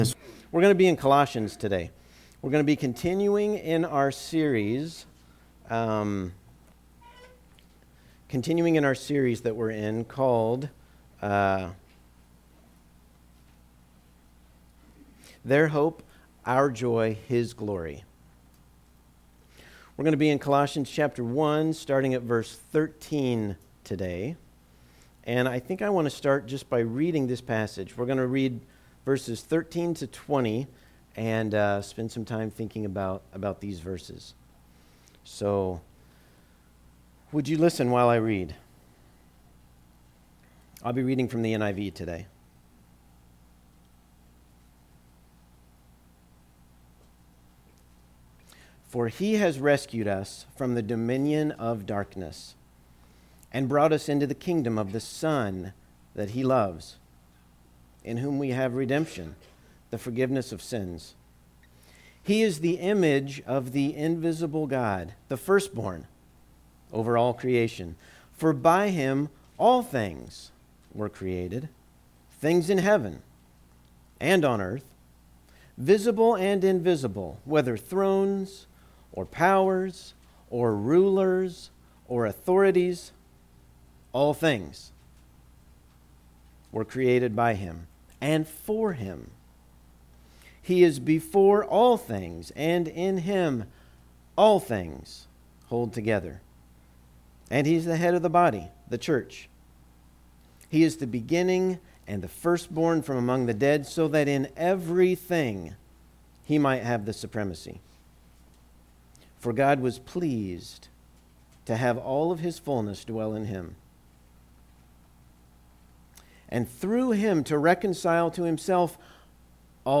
Psalm 89:26-27 Service Type: Sunday Morning What does Jesus bring to my life?